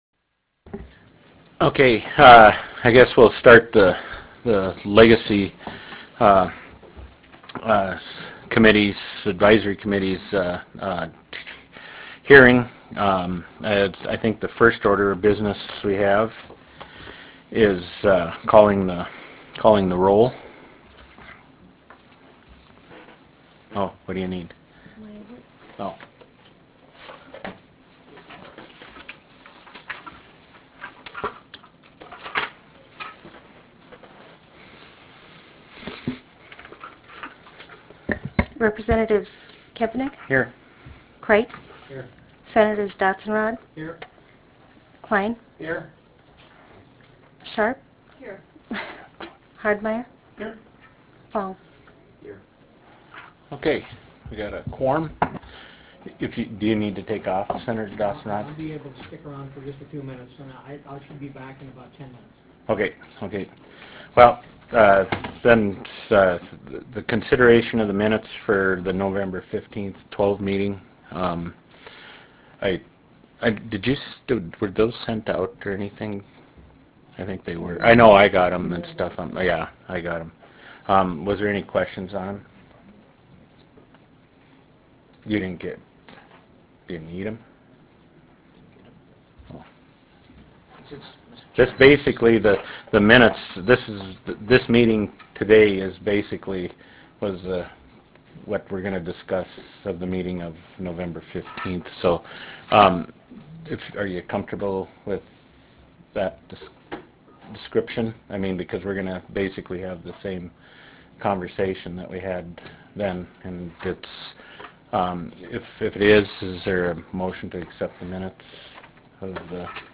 Medora Room State Capitol Bismarck, ND United States